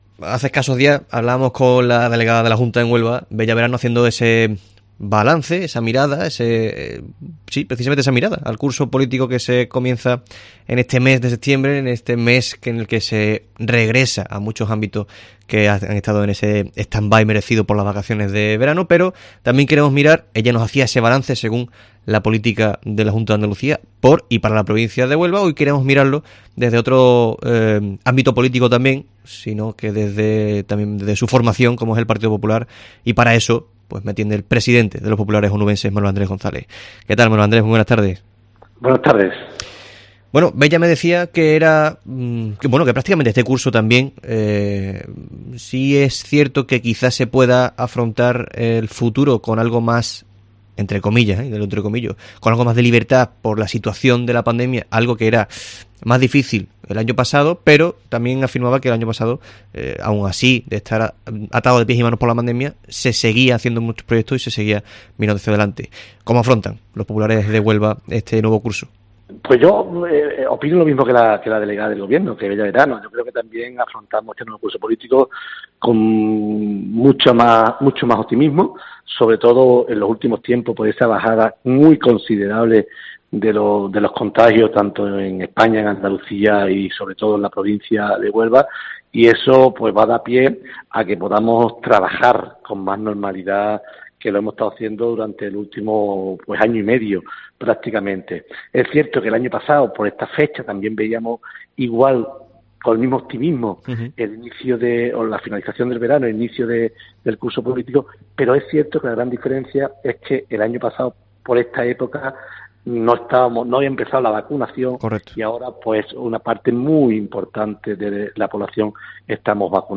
El presidente del PP Huelva, Manuel Andrés González, atiende la llamada de COPE Huelva para realizar una valoración y mirada al futuro con el nuevo...